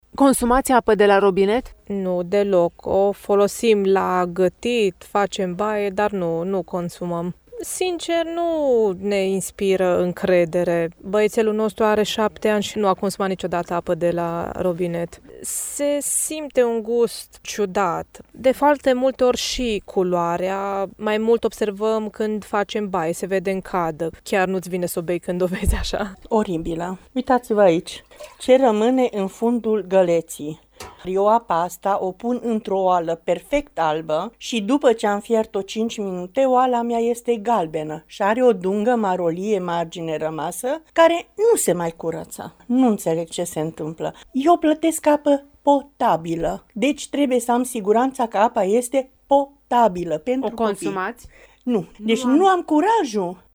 18-feb-vox-apa.mp3